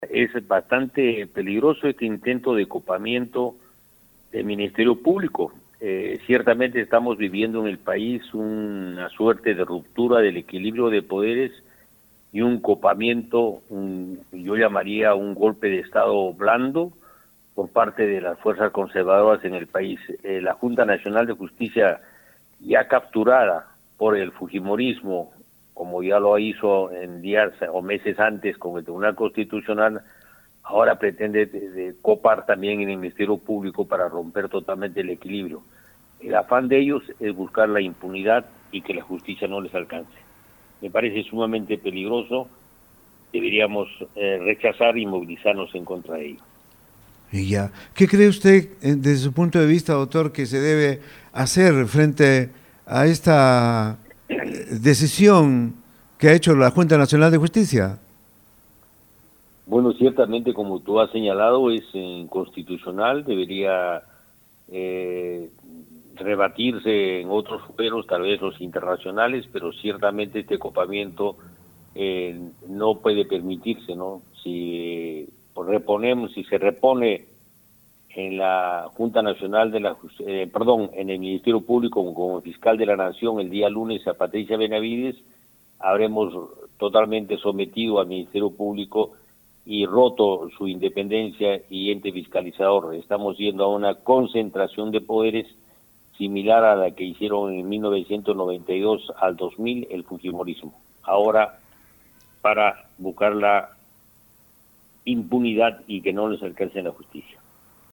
Al respecto, el excongresista Alberto Quintanilla en conversación con Radio Uno de Tacna, afirmó este sábado 14 que tal decisión de la JNJ representa la rotura del equilibrio de poderes y golpe de Estado blando por parte de las fuerzas conservadoras en el país.
4-EXCONGRESISTA-QUINTANILLA-SOBRE-CASO-BENAVIDES.mp3